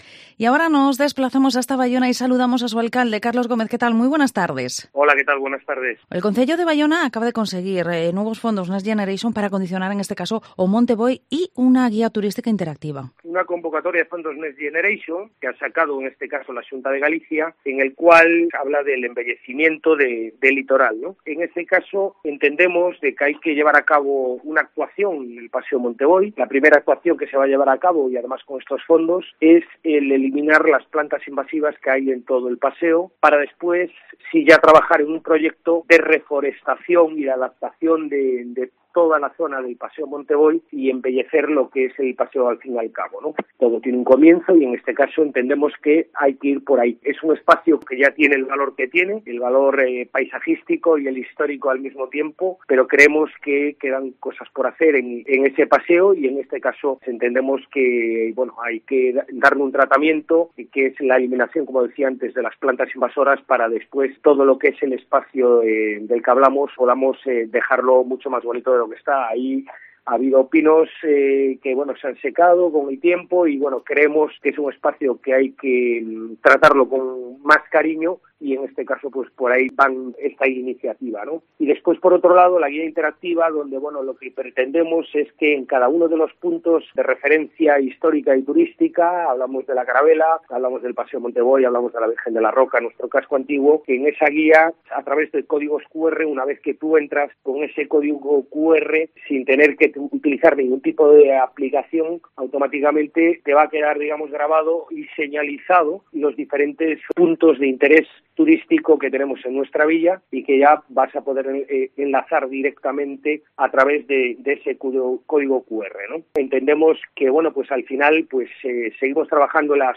Entrevista al Alcalde de Baiona, Carlos Gómez